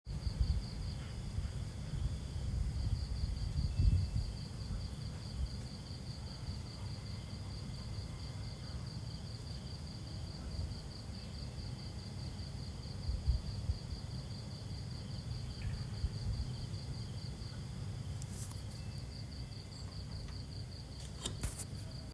nighttime crickets in my garden.m4a